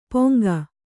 ♪ poŋga